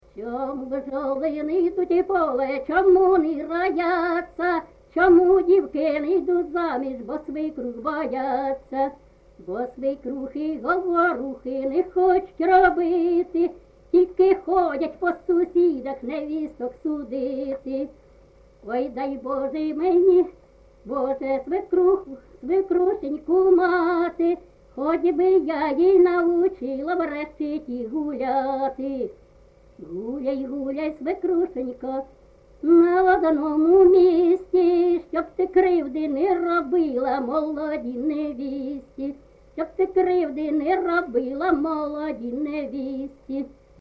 ЖанрПісні з особистого та родинного життя, Жартівливі
Місце записус-ще Михайлівське, Сумський район, Сумська обл., Україна, Слобожанщина